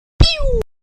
pew.mp3